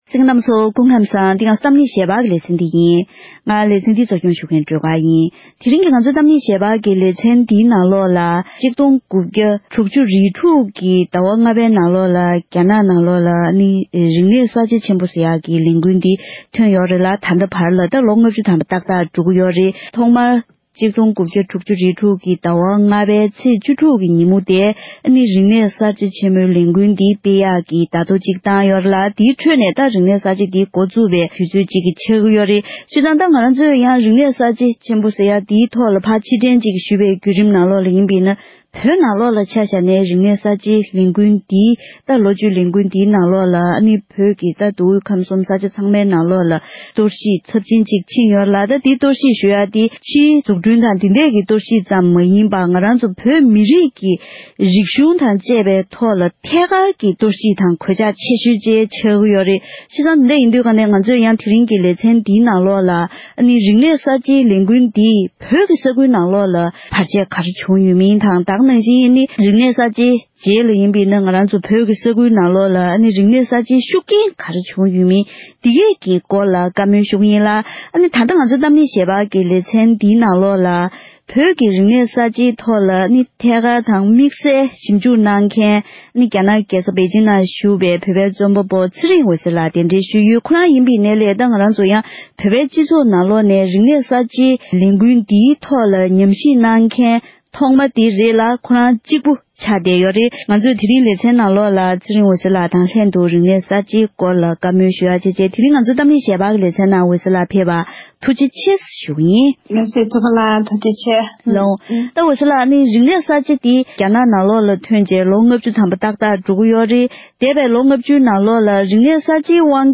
༄༅། །ཐེངས་འདིའི་གཏམ་གླེང་ཞལ་པར་གྱི་ལེ་ཚན་ནང་། རིག་གནས་གསར་བརྗེ་ཆེན་པོ་ཟེར་བའི་ལས་འགུལ་དེ་སྤེལ་ནས་ལོ་ངོ་ལྔ་བཅུ་འཁོར་བ་དང་བསྟུན། ལས་འགུལ་དེས་བོད་དང་བོད་མི་རིགས་ལ་ཚབས་ཆེའི་གནོད་འཚེ་ཇི་ལྟར་བྱུང་བའི་སྐོར་དང་། དམིགས་བསལ་བོད་ཀྱི་རྒྱལ་ས་ལྷ་སའི་ནང་ལས་འགུལ་དེ་ཇི་ལྟར་སྤེལ་བའི་སྐོར་གླེང་མོལ་ཞུས་པར་གསན་རོགས་ཞུ༎